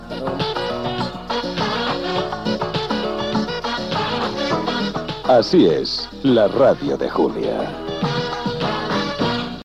Indicatiu del programa
Programa presentat per Julia Otero